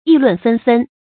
議論紛紛 注音： ㄧˋ ㄌㄨㄣˋ ㄈㄣ ㄈㄣ 讀音讀法： 意思解釋： 紛紛：眾多、雜亂的樣子。